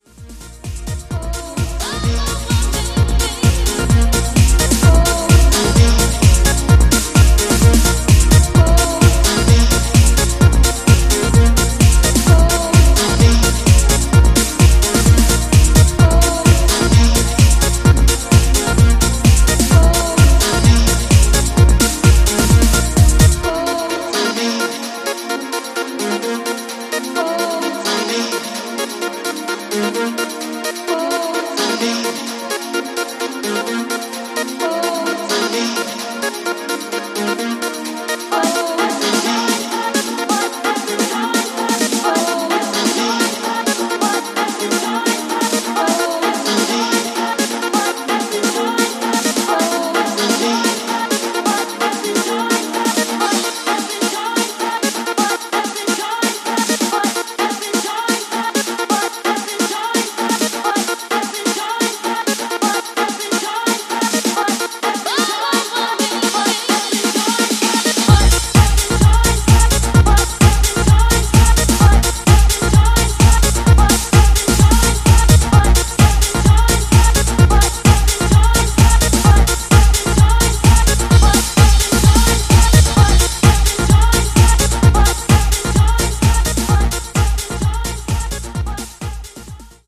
いずれの楽曲もソリッドでカラフル、そしてポジティヴなパワーで溢れたピークタイム・チューン！